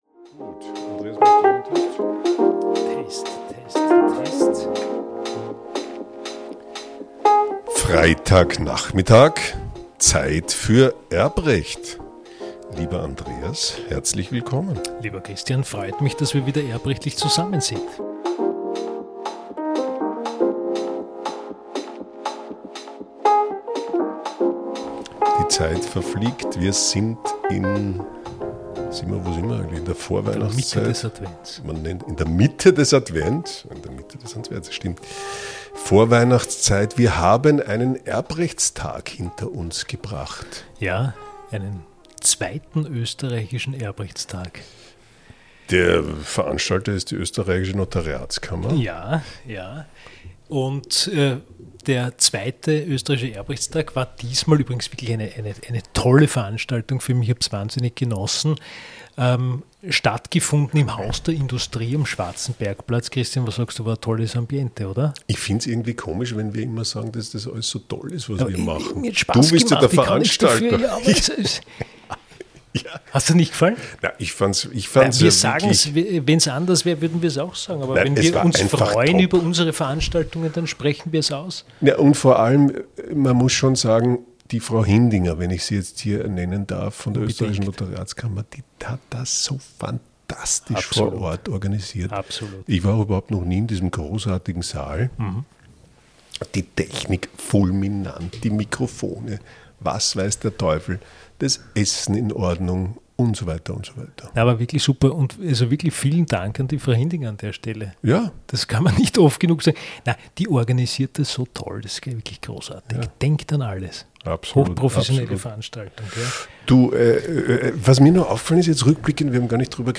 Text: Der Rückblick auf den 2. österreichischen Erbrechtstag führt uns zum Vortrag von Senatspräsidenten Musger. Es zeigt sich: Sogar ein Vergleich ist im Bereich des Erbrechts besonders kompliziert